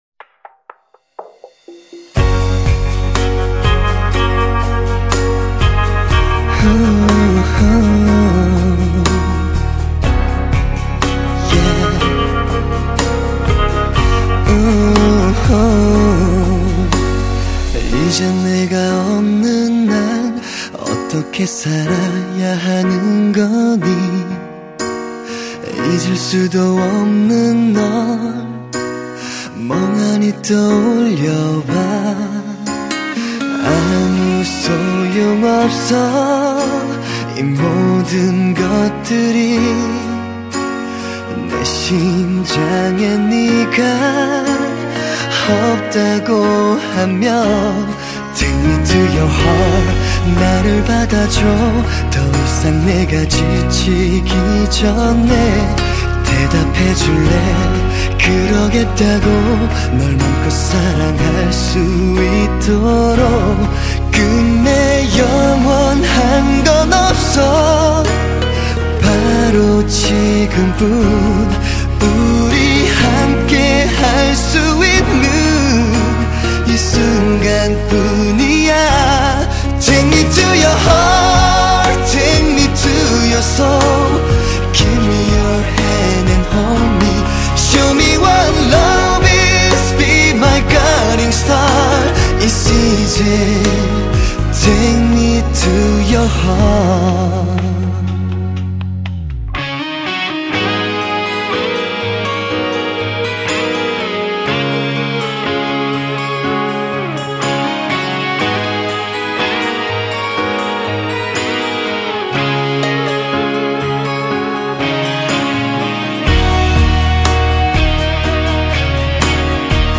西洋音樂